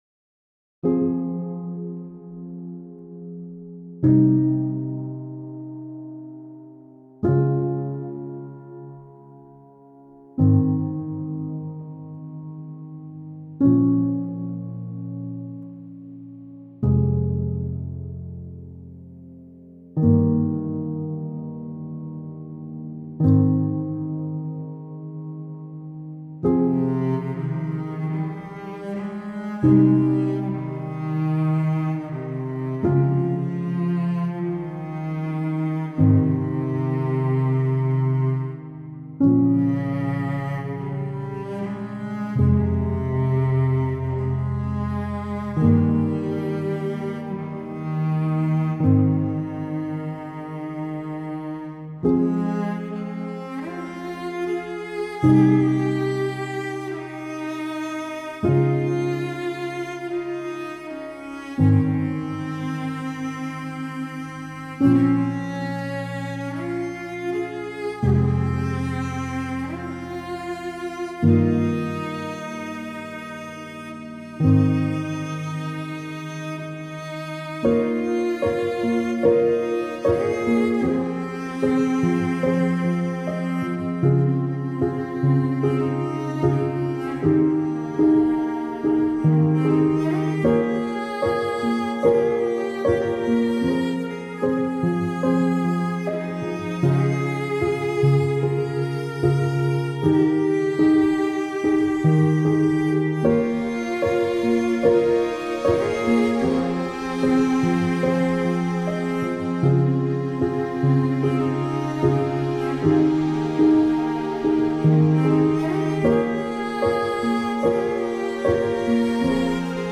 مالیخولیایی